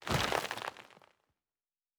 Wood 08.wav